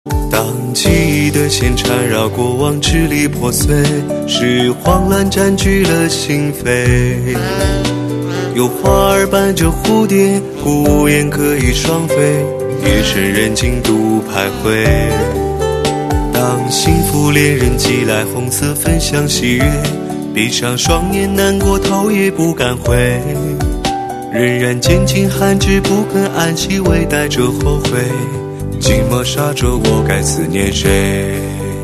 M4R铃声, MP3铃声, 华语歌曲 90 首发日期：2018-05-14 22:37 星期一